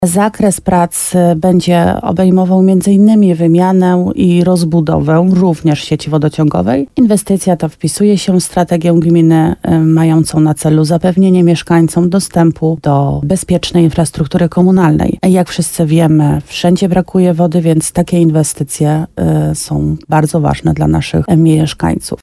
Celem inwestycji jest poprawa jakości wody i niezawodności jej dostaw. – Chodzi o odcinek 3 km – mówi wójt Marta Słaby.